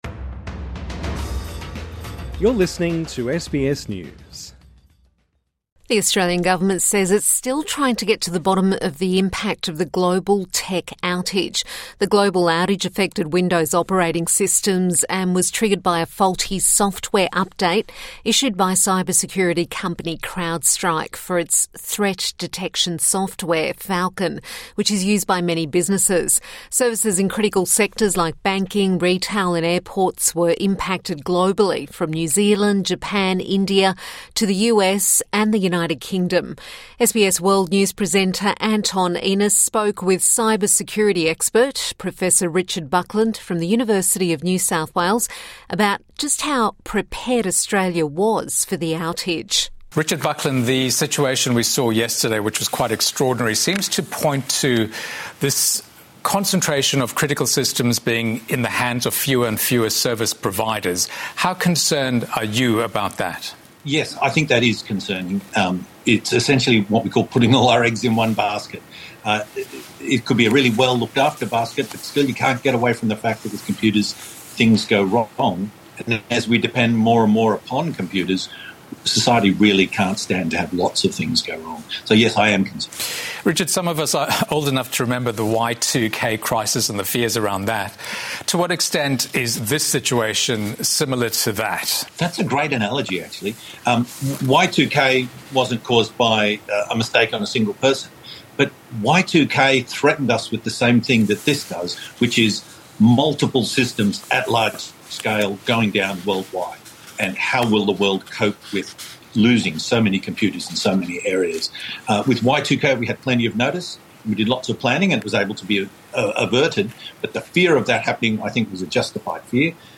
INTERVIEW: Is Australia well prepared for another IT outage?